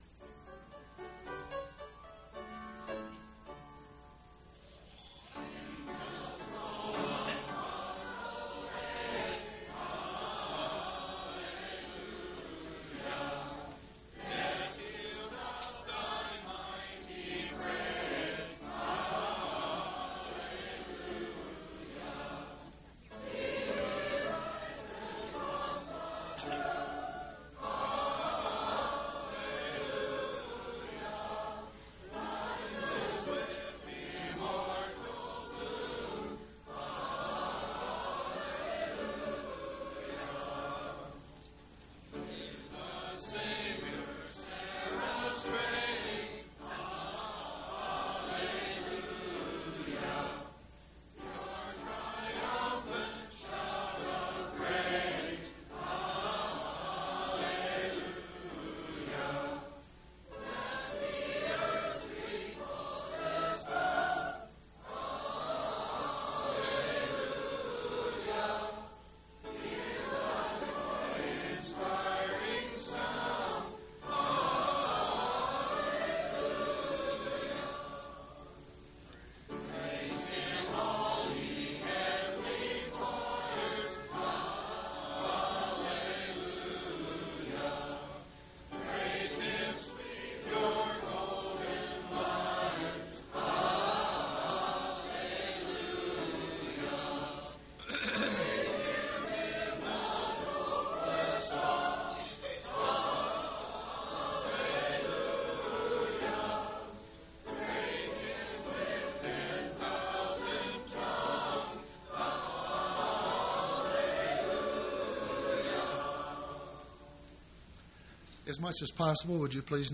3/31/1991 Location: Temple Lot Local (Conference) Event: General Church Conference